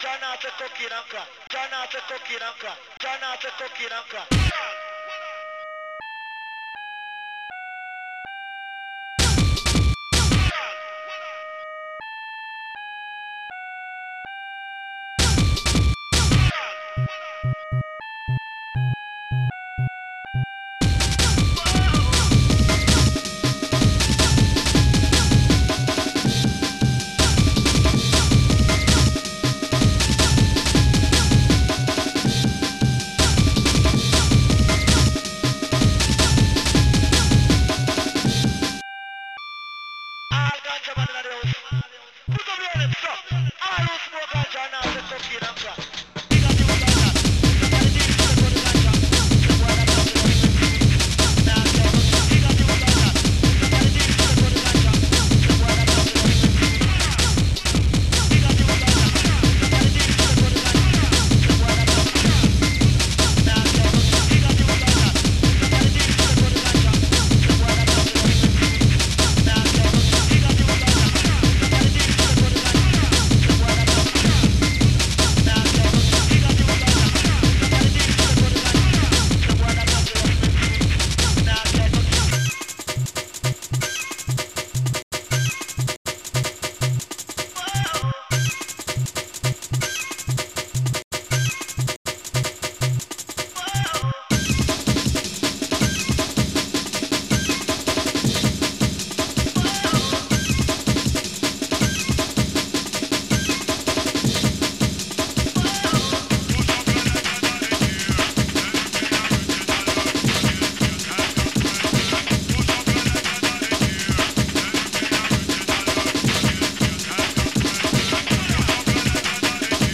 Protracker and family
bd 2 3 crash
jungle compo'96